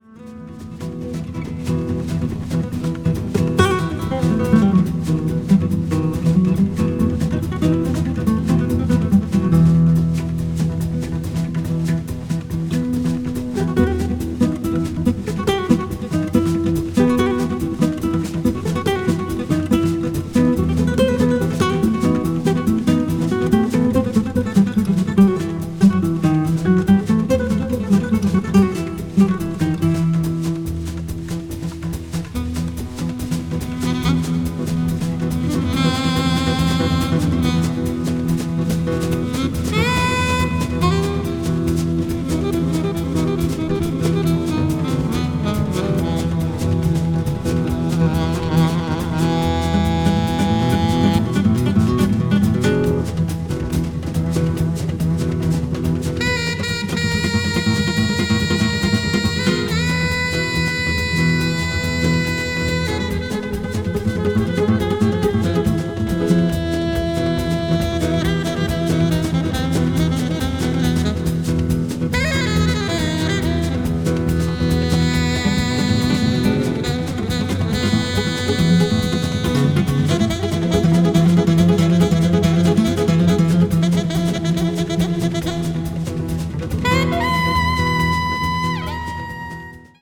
media : EX-/EX-(わずかにチリノイズが入る箇所あり,再生音に影響ない薄いスリキズあり)